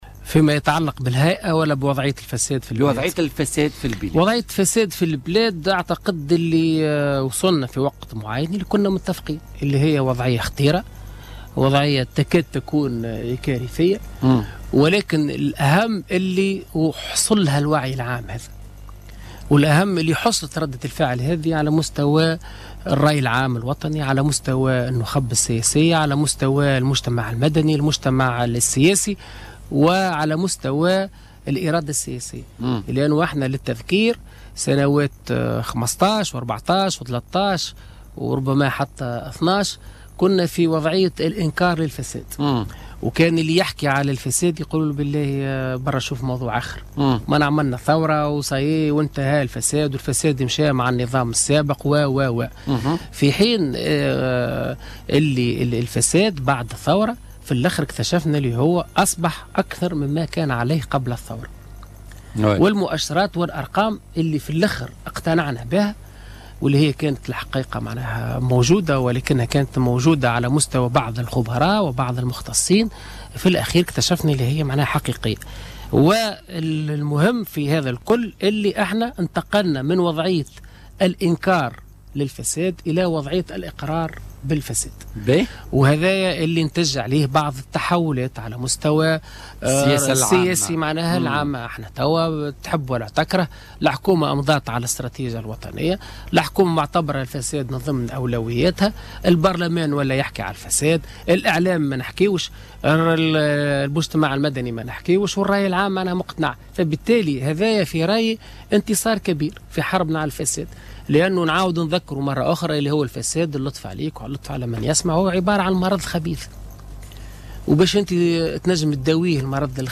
وذكّر ضيف "بوليتيكا" على "الجوهرة أف أم" أن وضعية الفساد تكاد أن تكون كارثية وهو أمر واقع وتم اقراره ليتحول من وضعيى الانكار إلى وضعيى الاقرار بالفساد بحسب تعبيره، واصفا هذه الآفة بالمرض الخبيث.